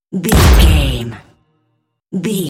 Dramatic hit drum metal debris
Sound Effects
heavy
intense
dark
aggressive
hits